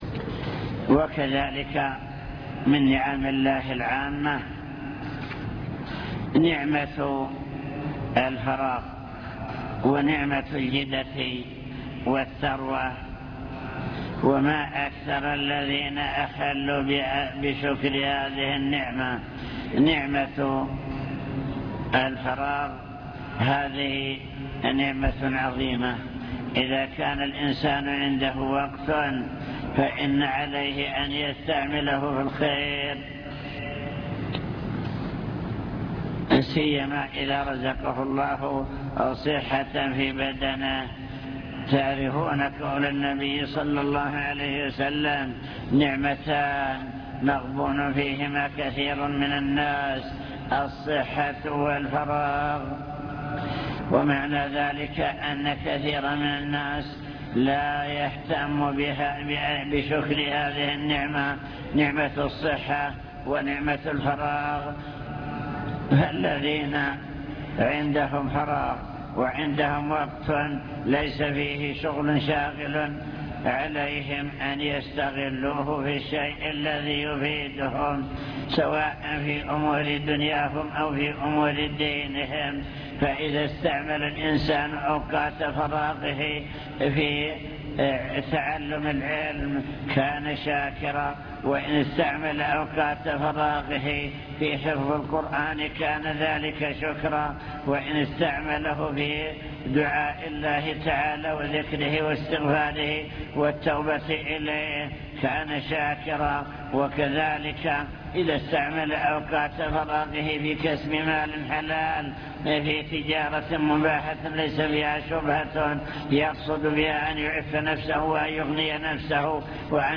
المكتبة الصوتية  تسجيلات - محاضرات ودروس  محاضرة بعنوان شكر النعم (1) ذكر نماذج لنعم الله تعالى العامة وكيفية شكرها